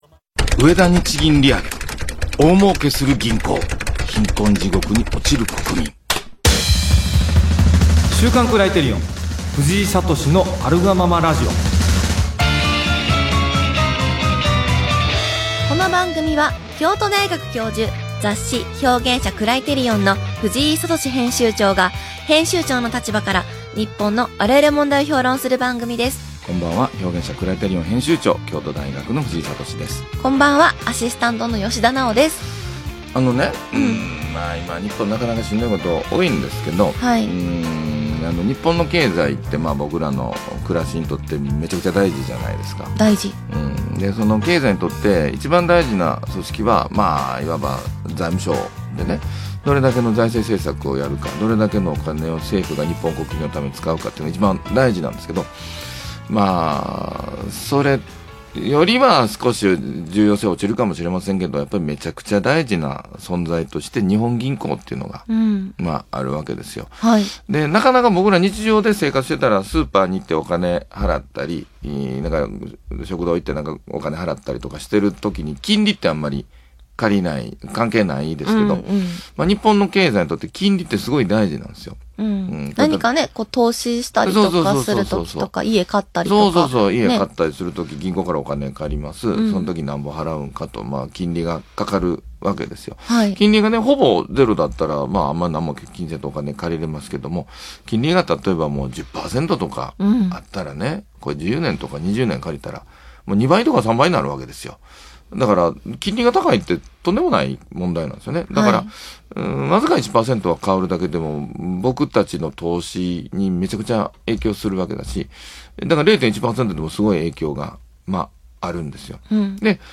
【ラジオ】植田日銀利上げ，大儲けする銀行，貧困地獄に落ちる国民．